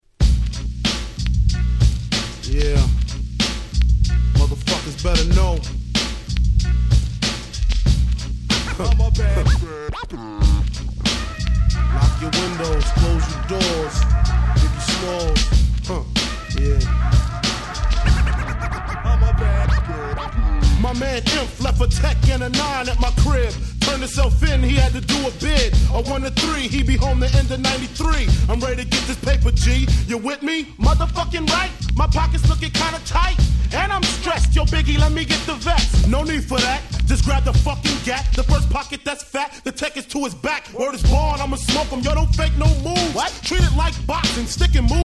94’ Super Hit 90's Hip Hop LP !!
90's Boom Bap